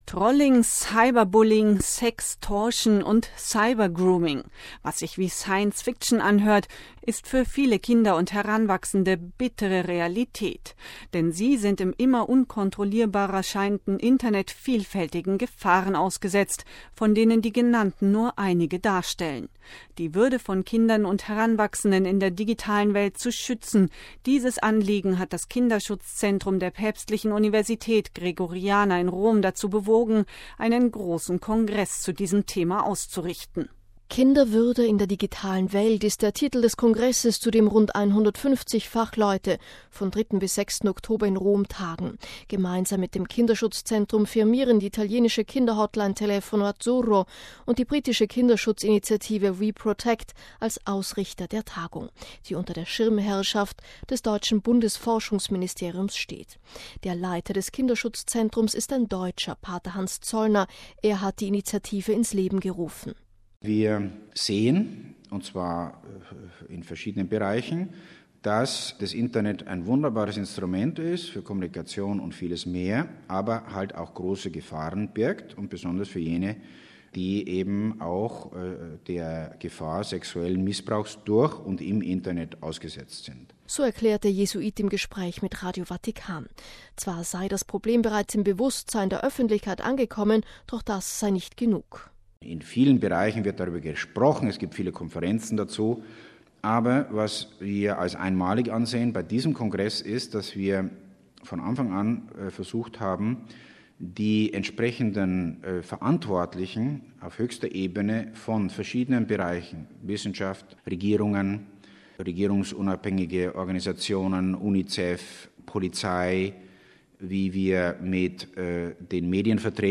„Wir sehen, und zwar in verschiedenen Bereichen, dass das Internet ein wunderbares Instrument für Kommunikation und vieles mehr ist, aber eben auch große Gefahren birgt - besonders für jene, die der Gefahr sexuellen Missbrauchs durch und im Internet ausgesetzt sind,“ erklärt der Jesuit im Gespräch mit Radio Vatikan.